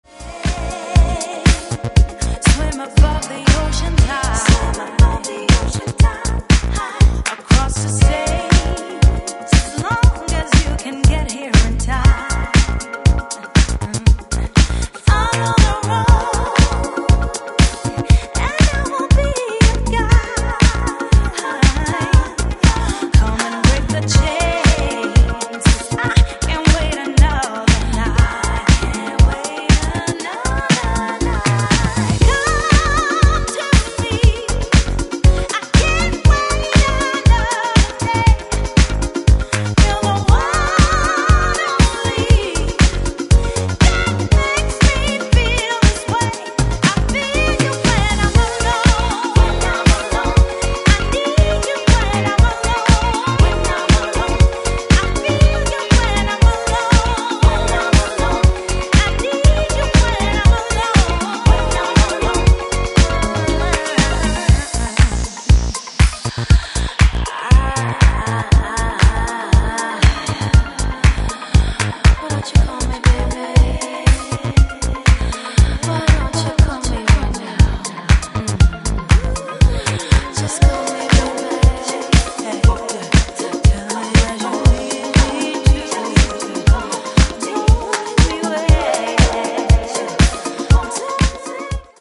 ジャンル(スタイル) NU DISCO / DISCO / HOUSE / RE-EDIT